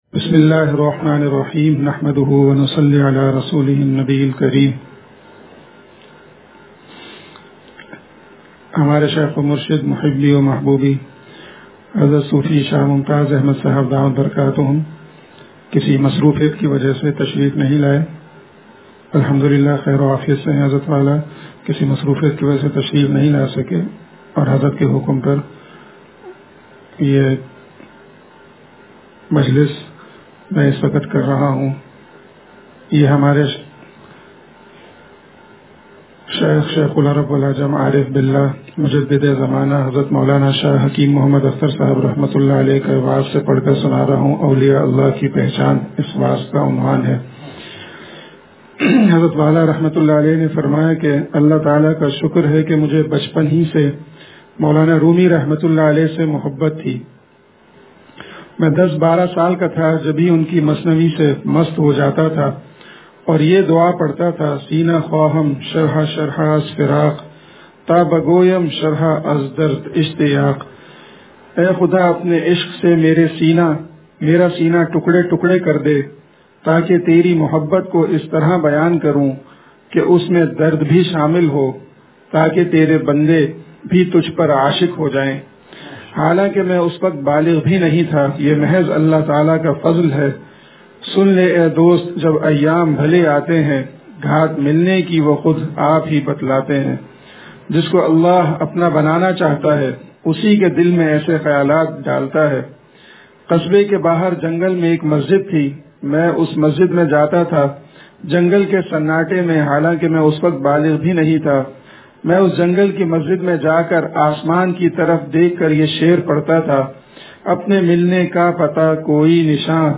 وعظ اولیا اللہ کی پہچان سے – دنیا کی حقیقت – نشر الطیب فی ذکر النبی الحبیب صلی اللہ علیہ وسلم